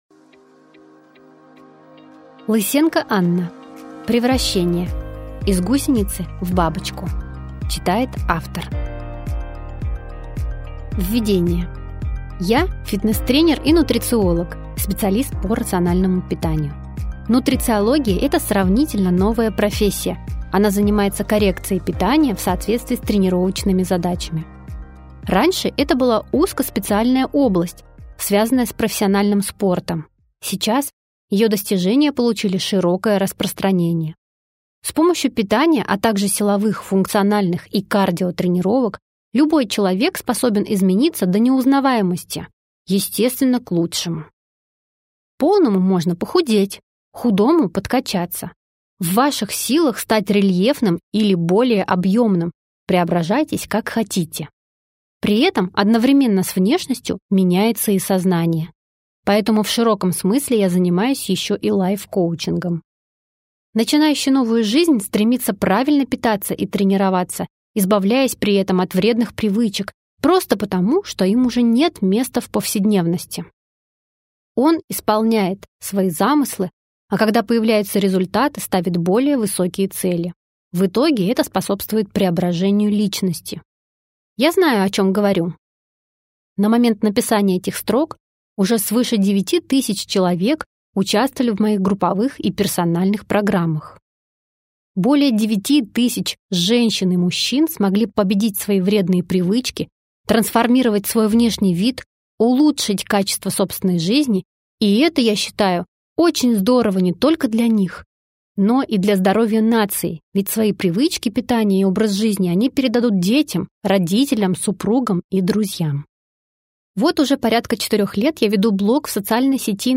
Аудиокнига Превращение. Из гусеницы в бабочку | Библиотека аудиокниг